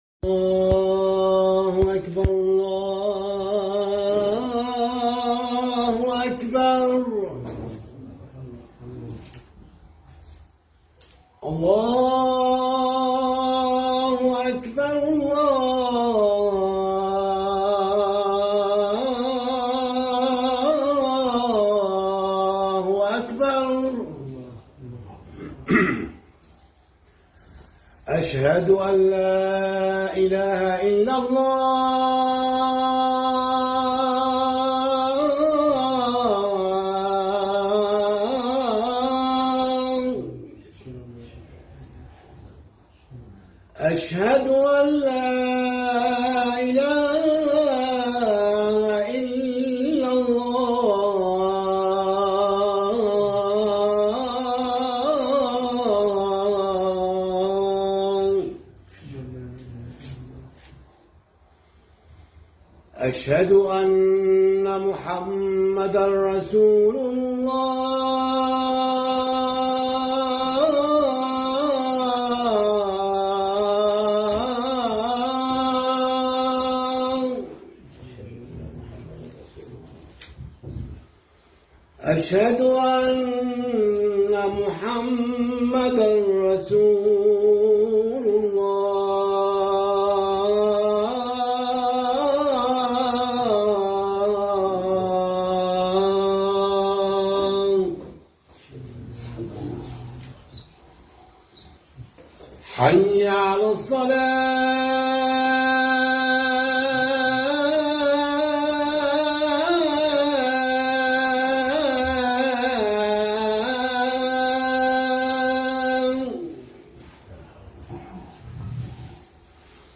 خطبة الجمعة بعنوان ( من محمد؟صلى الله عليه وسلم ) من الجالية المصرية باسطنبول